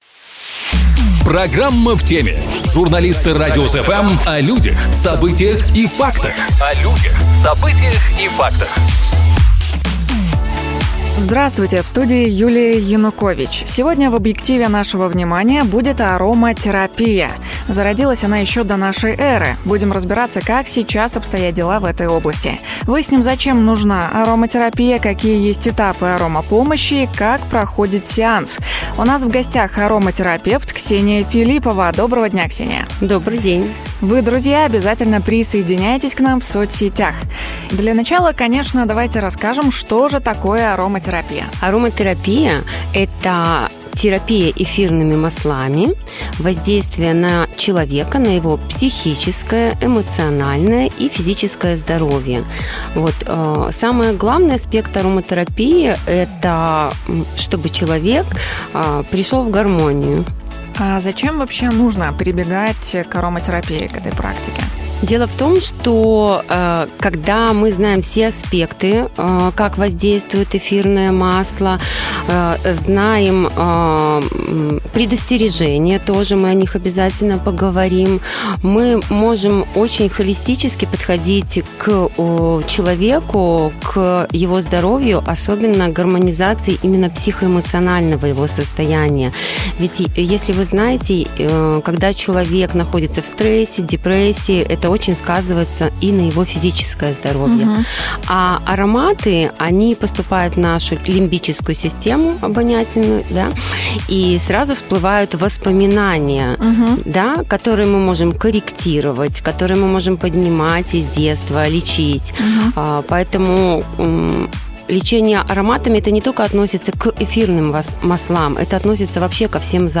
У нас в гостях - ароматерапевт